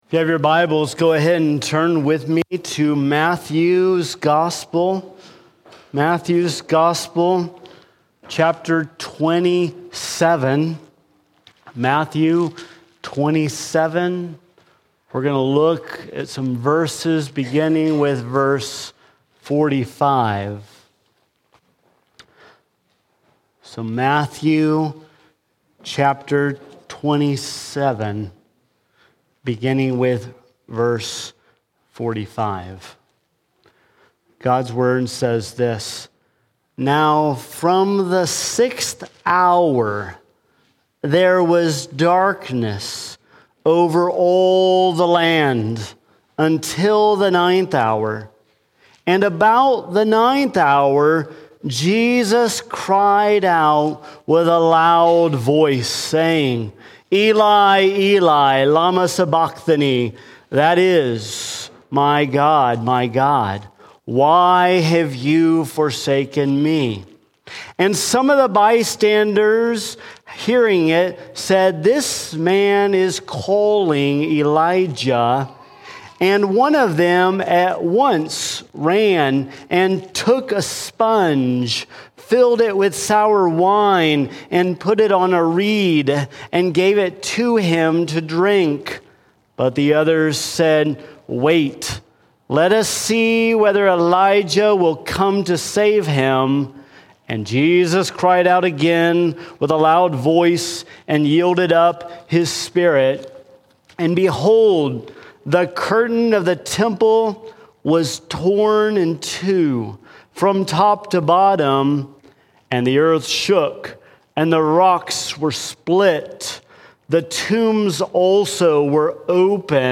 Easter Weekend Sermons
Good Friday 2026